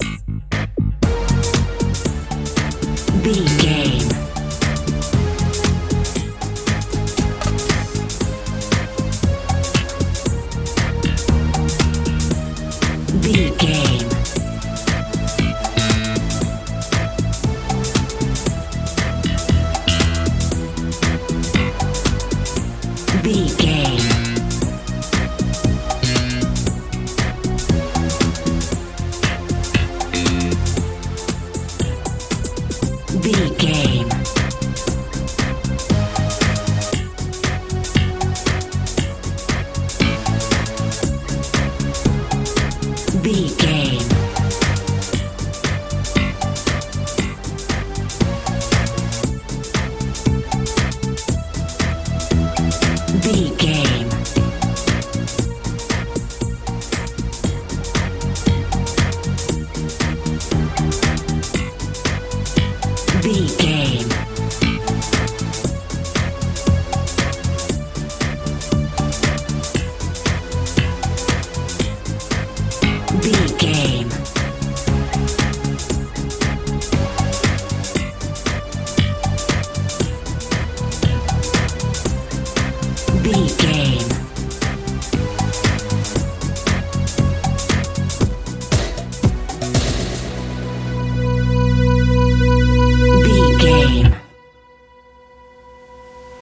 pop dance feel
Ionian/Major
mystical
futuristic
synthesiser
bass guitar
electric piano
80s
90s
strange